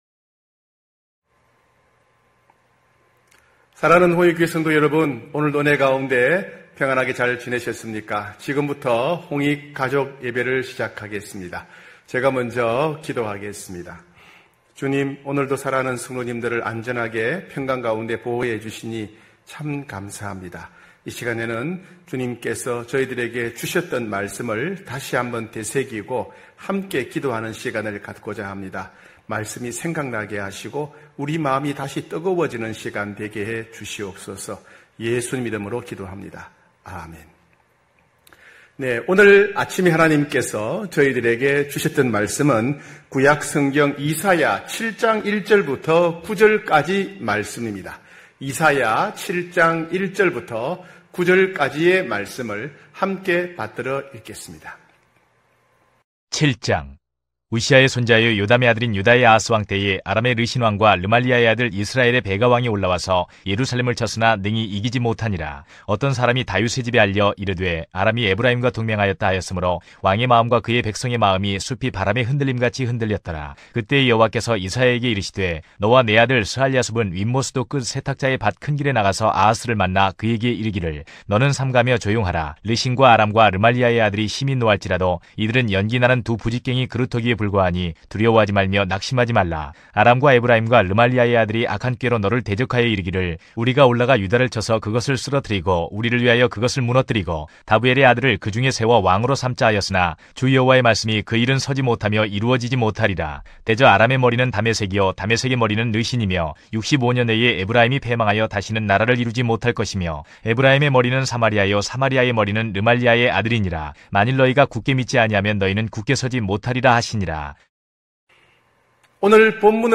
9시홍익가족예배(7월18일).mp3